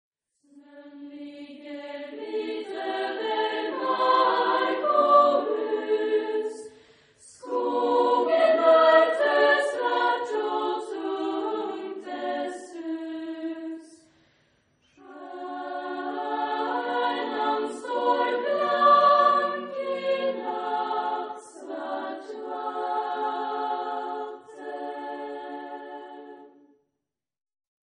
Genre-Style-Form: Christmas song
Mood of the piece: cantabile ; calm
Type of Choir: SSAA  (4 children OR women voices )
Tonality: D major ; D dorian